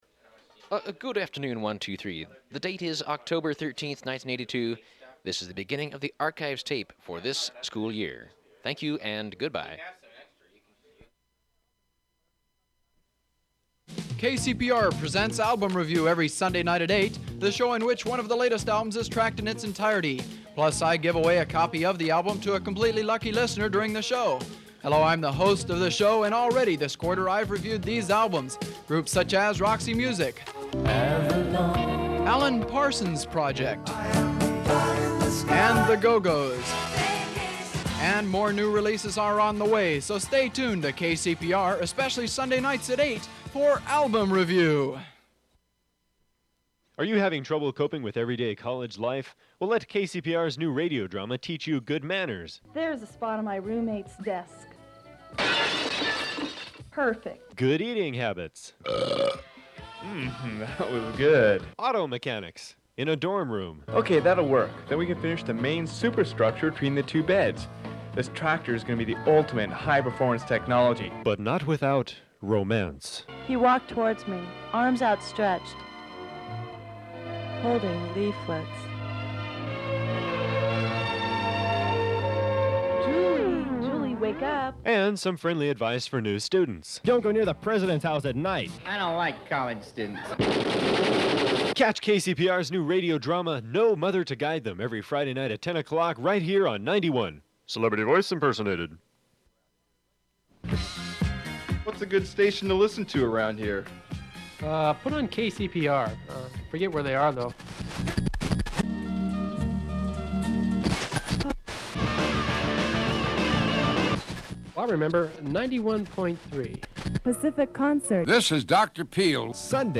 KCPR Promo (Scanning Radio Dial)